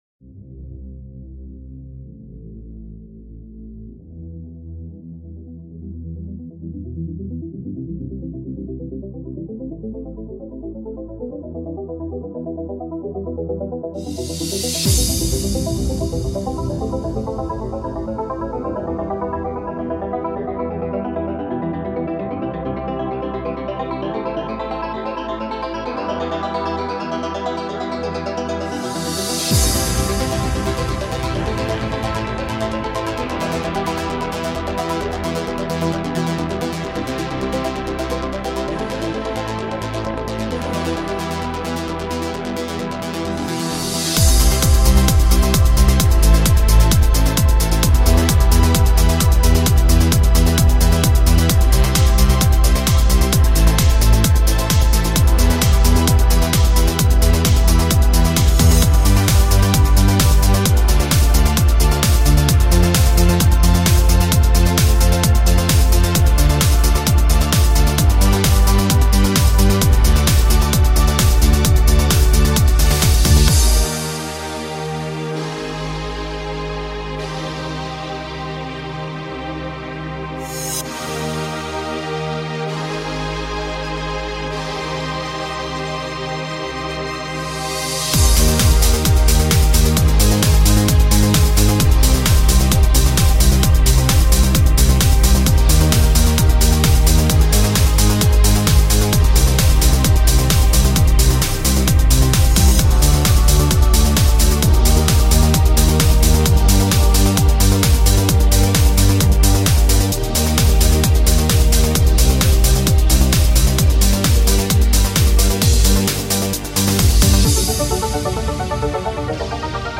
faster, remixed and without singing.